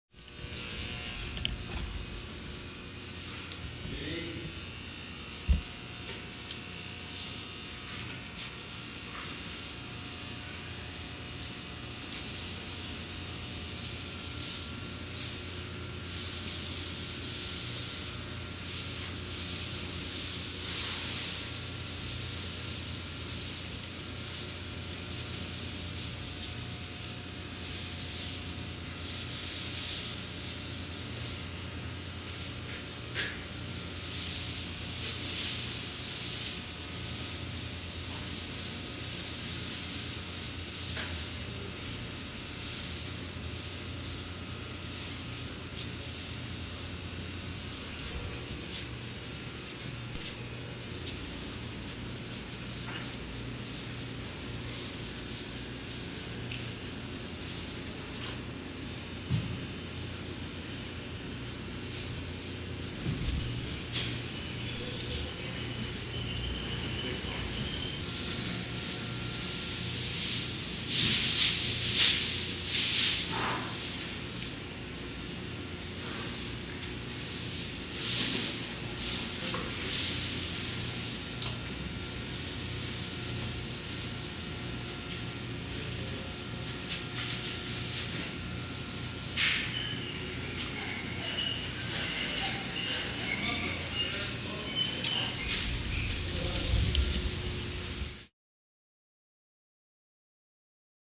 جلوه های صوتی
دانلود صدای فیل 3 از ساعد نیوز با لینک مستقیم و کیفیت بالا
برچسب: دانلود آهنگ های افکت صوتی انسان و موجودات زنده دانلود آلبوم صدای حیوانات وحشی از افکت صوتی انسان و موجودات زنده